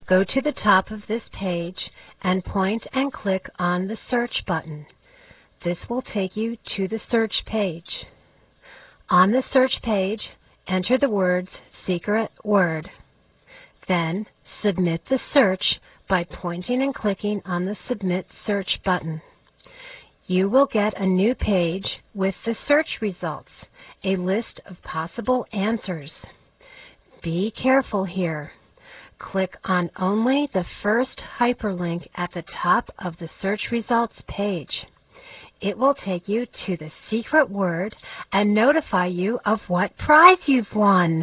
Sound: Listen to Klio (a Muse!) tell you the instructions (41").